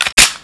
assets/pc/nzp/sounds/weapons/colt/slide.wav at 6d305bdbde965e83d143ab8cd4841a6c7b68160c
slide.wav